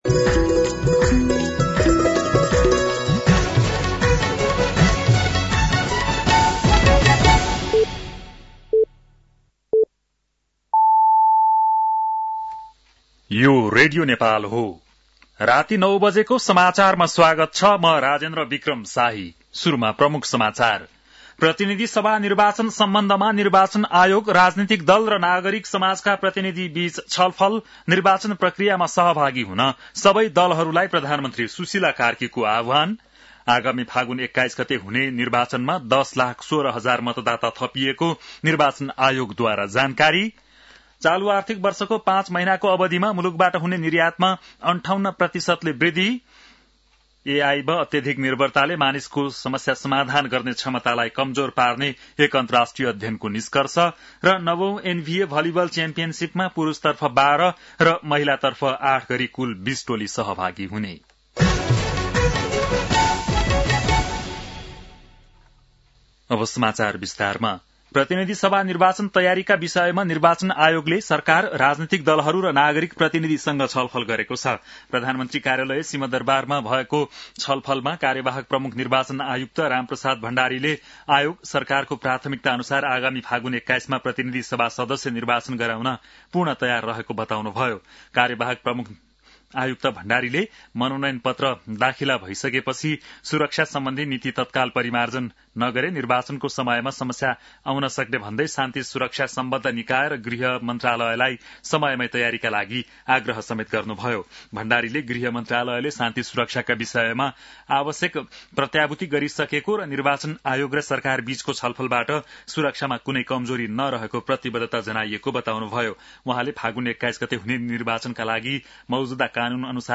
An online outlet of Nepal's national radio broadcaster
बेलुकी ९ बजेको नेपाली समाचार : ७ पुष , २०८२